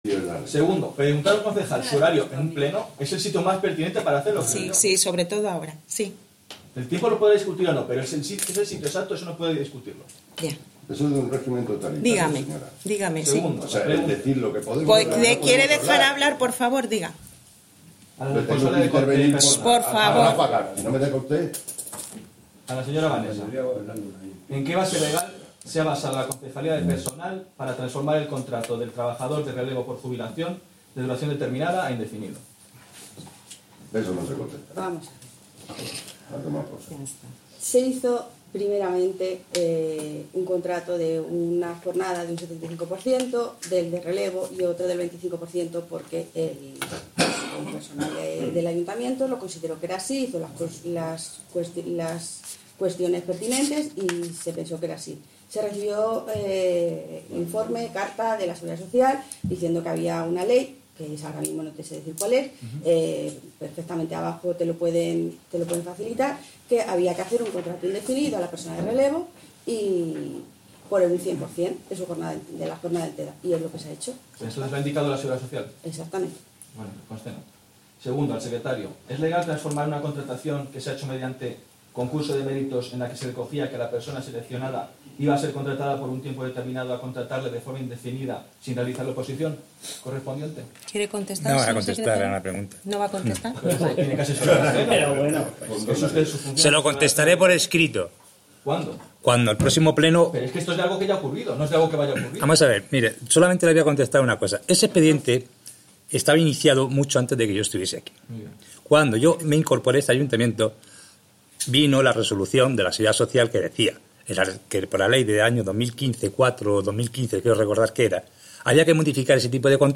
Audio del Pleno Ordinario de 27 de octubre de 2017